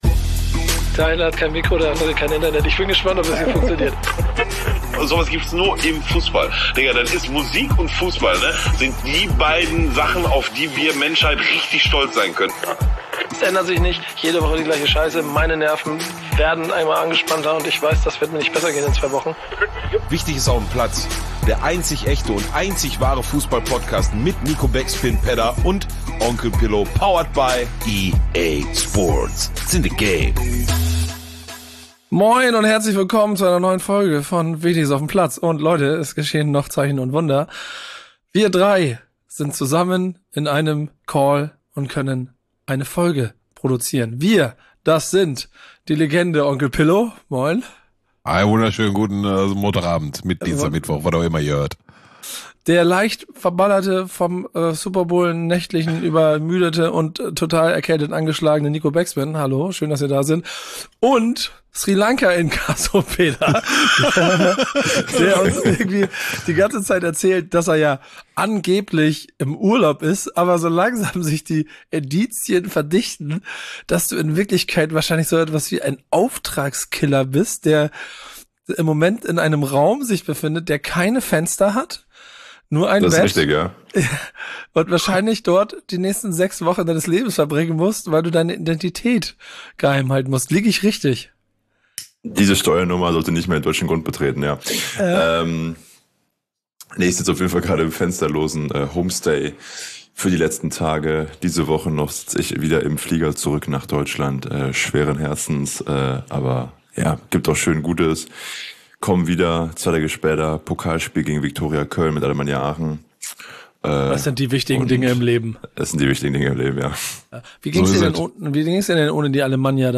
Unfassbar, eine Folge zu dritt. Langsam kommen wir wieder in unseren Rhythmus und sprechen direkt über internationale und nationale Geschehnisse der vergangenen Woche.